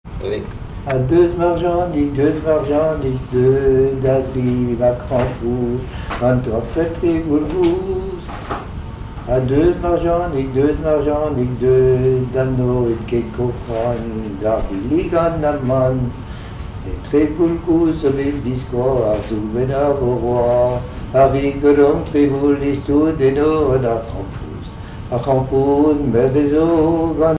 Chansons en breton
Pièce musicale inédite